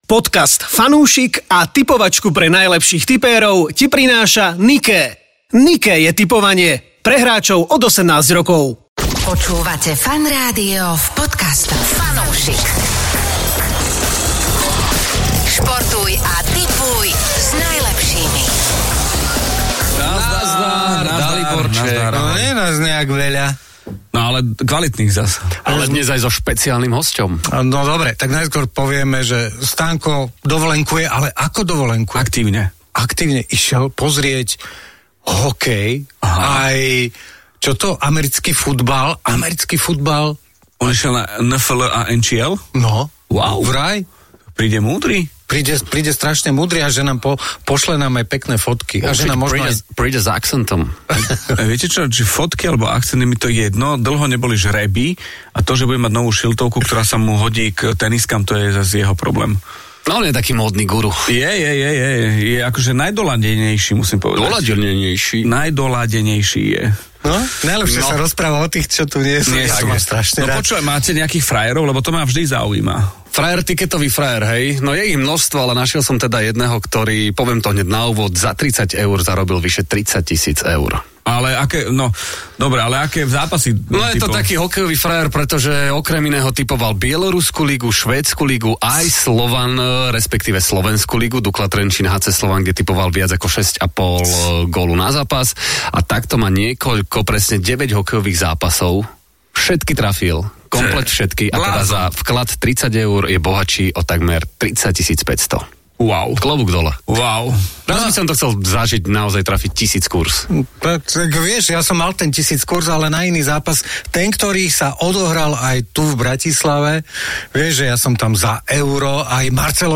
Daj si fanúšikovskú debatku o športe a tipovaní. Toto je podcastová šou od funúšikov pre funúšikov.